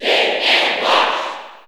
Mr._Game_&_Watch_Cheer_English_SSB4_SSBU.ogg